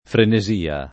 frenesia [ frene @& a ] s. f.